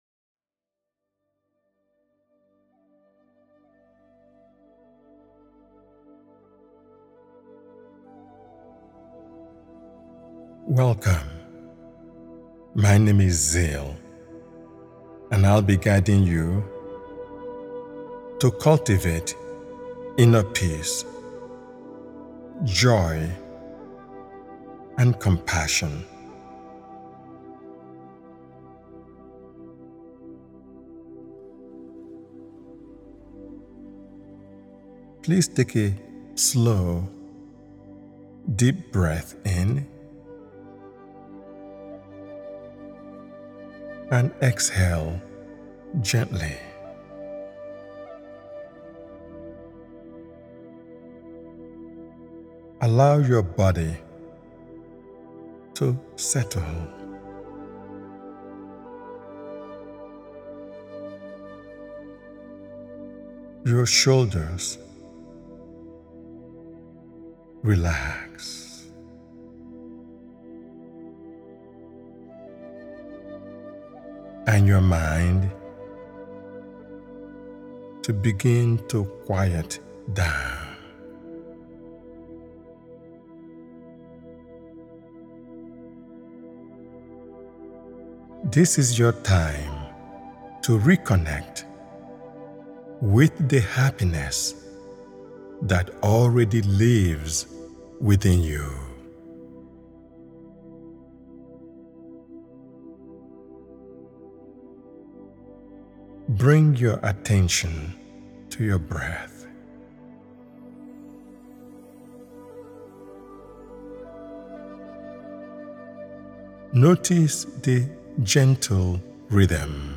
In a world that often feels rushed, demanding, and emotionally noisy, this heart-centered guided meditation offers a gentle pause—an invitation to return to yourself with kindness, presence, and compassion.